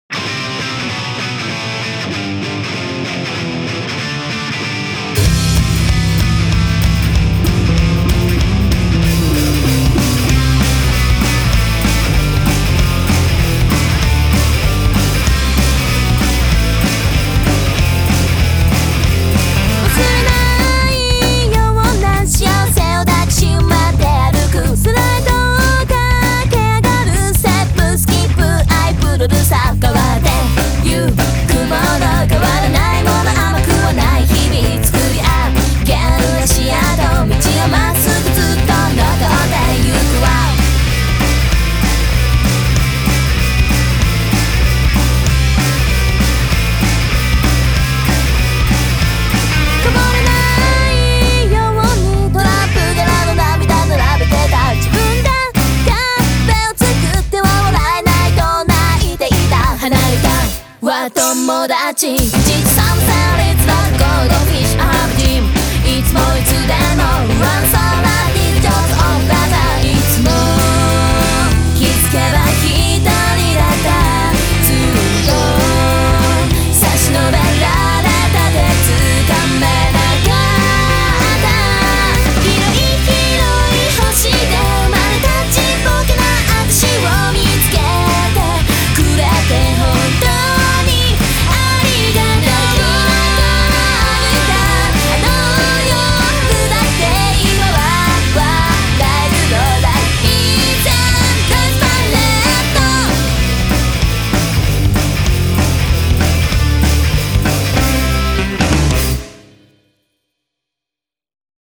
BPM192
Audio QualityPerfect (High Quality)